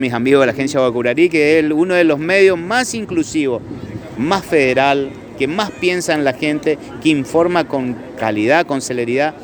El Ministro de Salud Pública de Misiones, Dr. Oscar Alarcón, dialogó en exclusiva con la ANG sobre el inminente balotaje presidencial, destacando a Sergio Massa como el mejor candidato para los intereses misioneros.